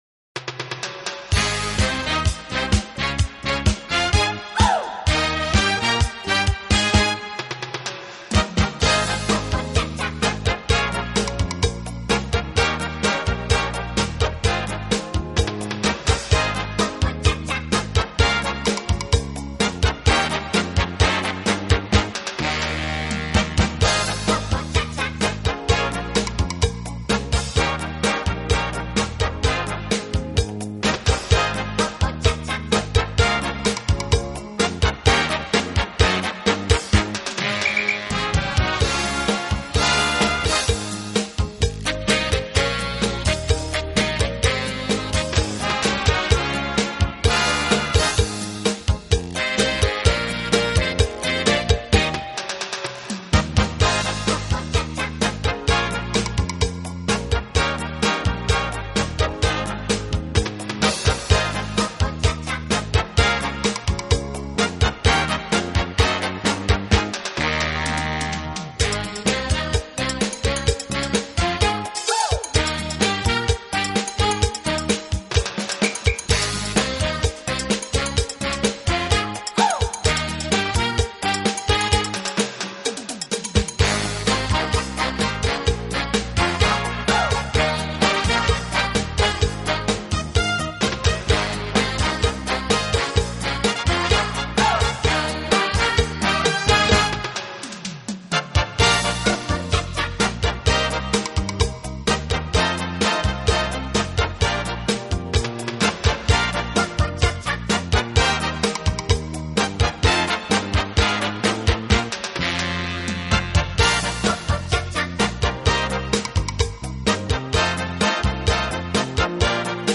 有动感，更有层次感；既有激情，更有浪漫。
Cha cha cha